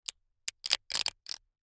chip_stack.mp3